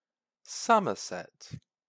Somerset (/ˈsʌmərsɪt, -sɛt/
En-uk-Somerset.ogg.mp3